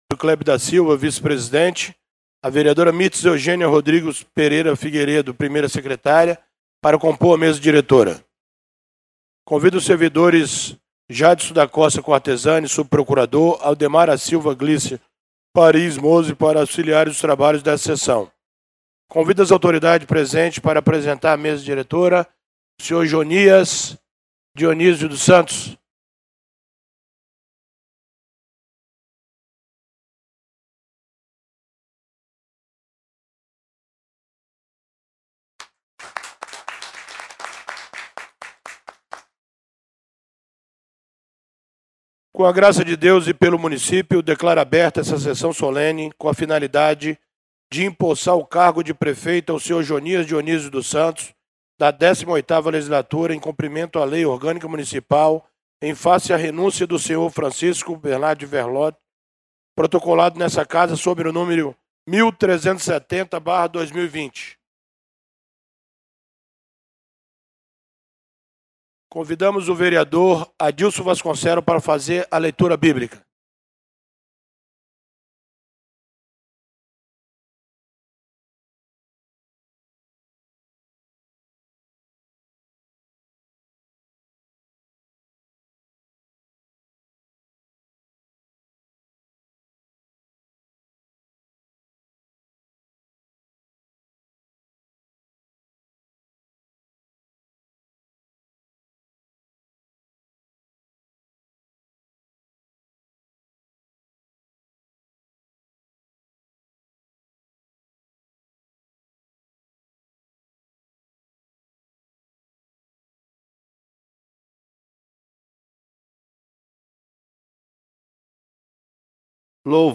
Sessão Solene posse Vice-Prefeito dia 01 de dezembro de 2020